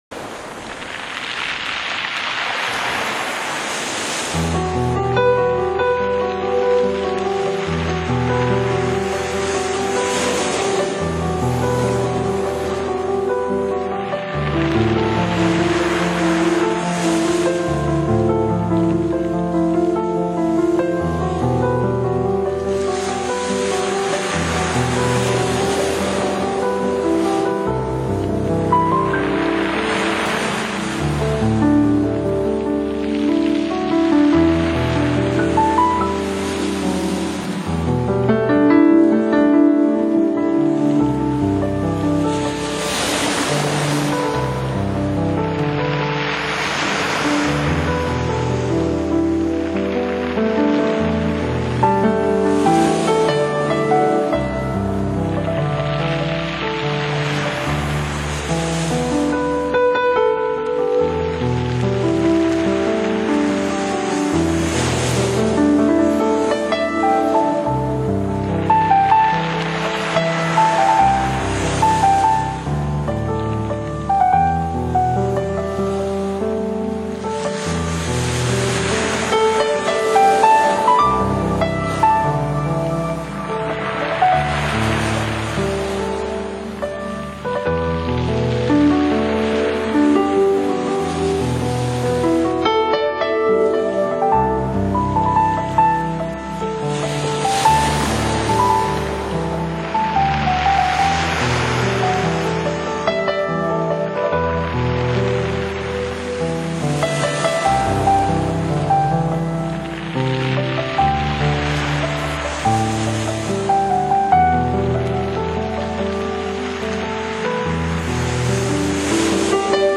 New Age、轻爵士、佛朗明哥吉他、古典钢琴小品、民谣风…10首音乐10种风格，实在好听的创作曲献给天下知音人。
钢琴